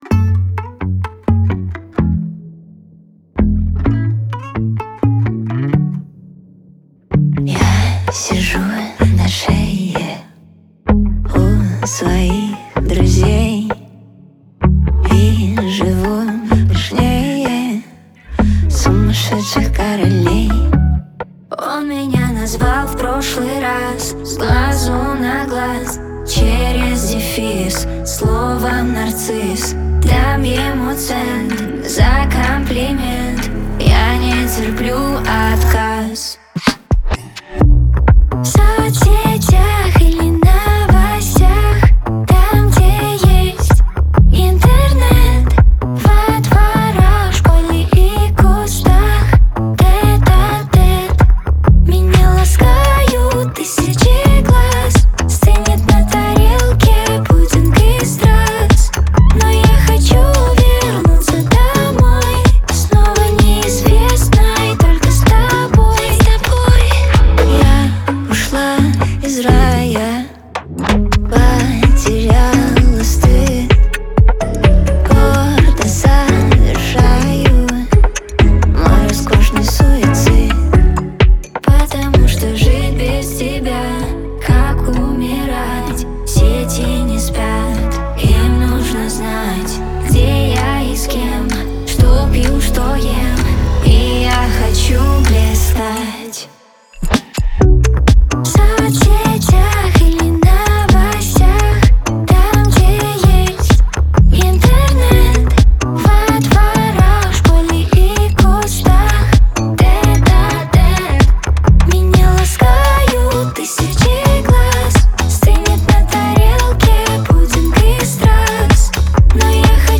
это яркая и энергичная композиция в жанре поп-музыки